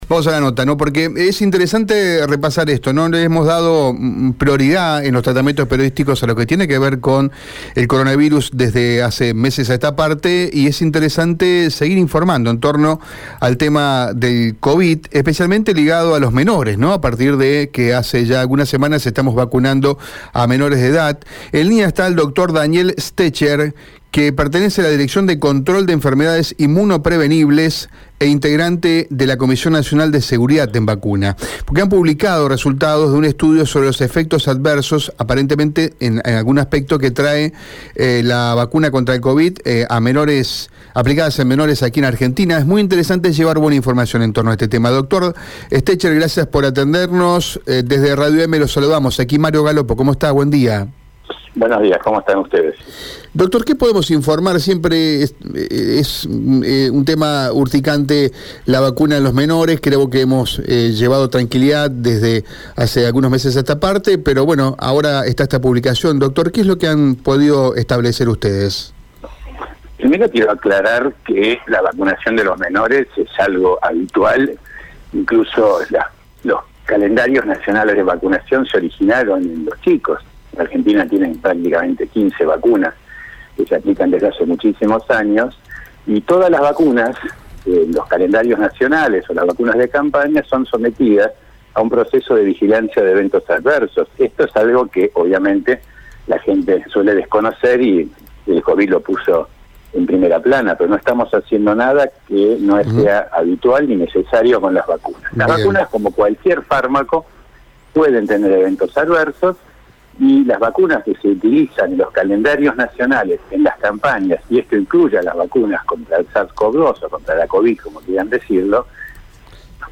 Entrevista: Las vacunas aplicadas en niñas, niños y adolescentes tienen un perfil adecuado | Radio EME